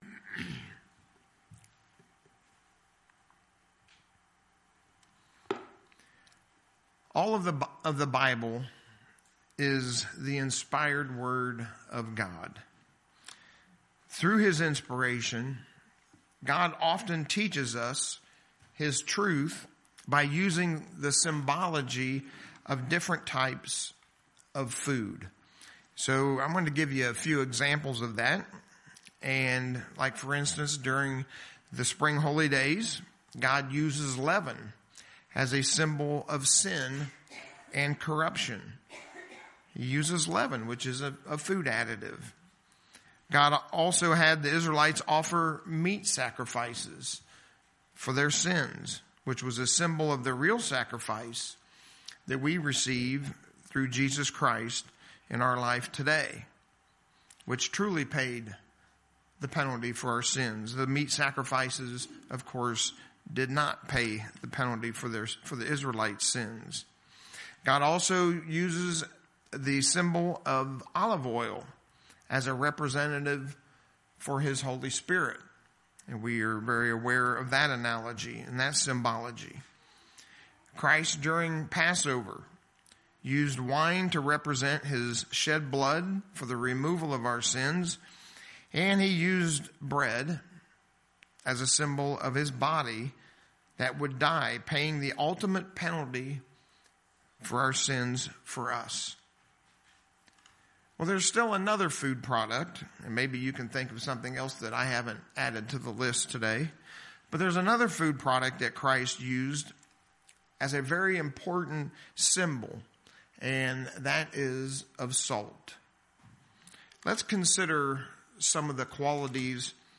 What is the significance of this statement by Jesus, and how should it be applied towards a Christians life? In the sermon today, we'll see the message that Jesus was offering to His people.
Given in Lehigh Valley, PA